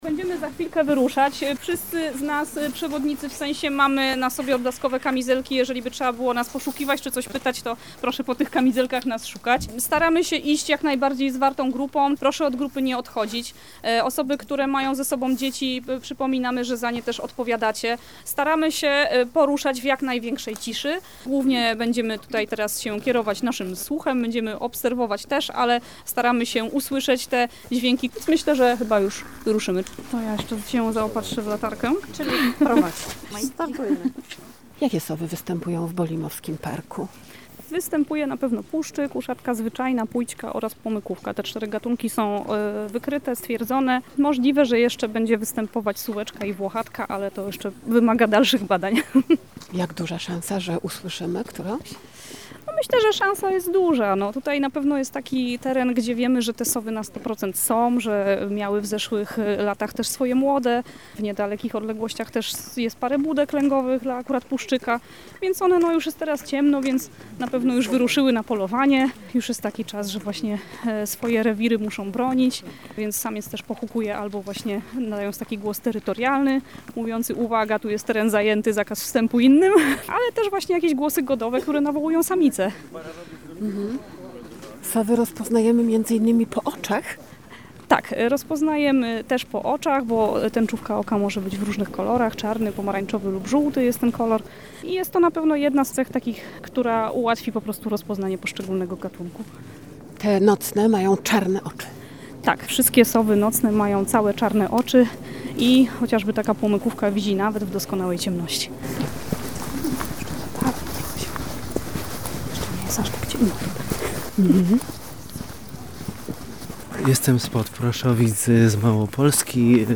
Zapraszamy na nocny spacer po lesie w poszukiwaniu sów